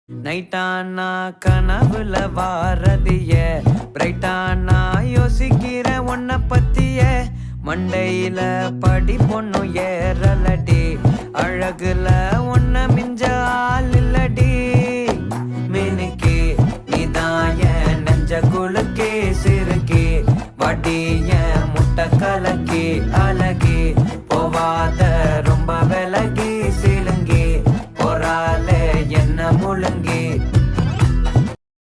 party song ringtone
dance ringtone download